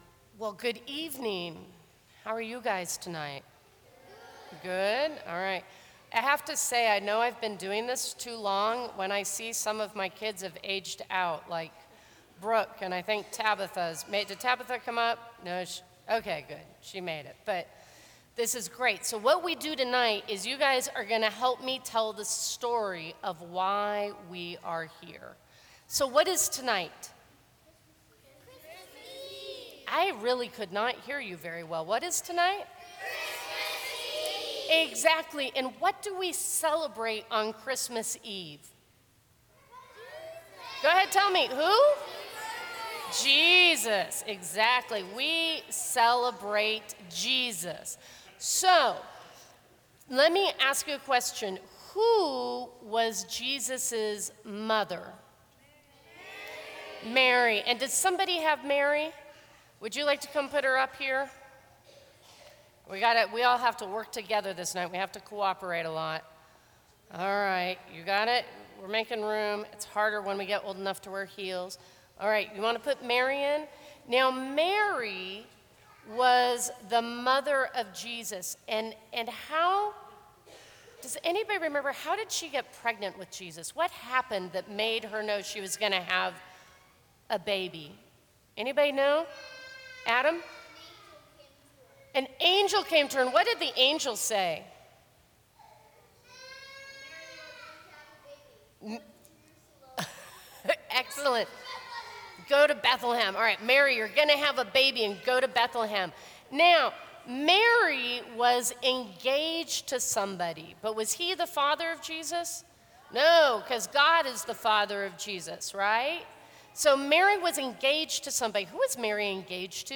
Christmas Eve Family Service.
Sermons from St. Cross Episcopal Church Christmas Eve 4PM 2013 Dec 26 2013 | 00:10:10 1x 00:00 / 00:10:10 Subscribe Share Apple Podcasts Spotify Overcast RSS Feed Share Link Embed